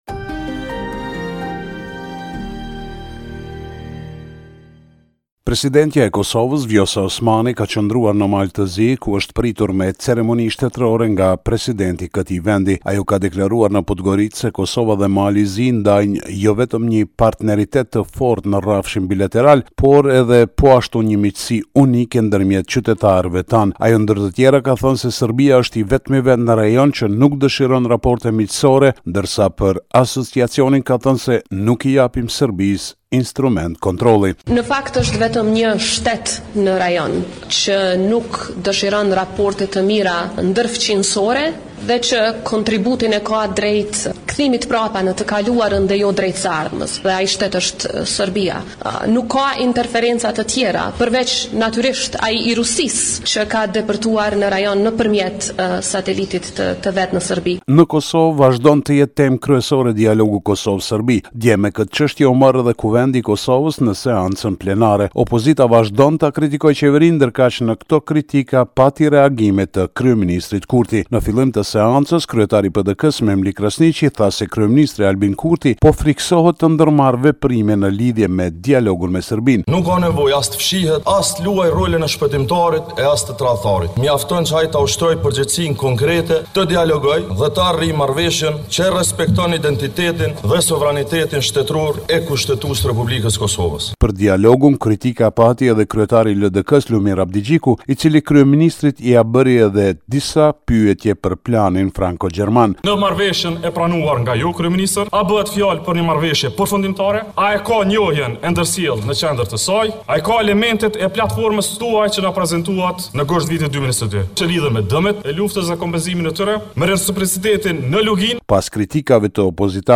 Raporti me të rejat më të fundit nga Kosova.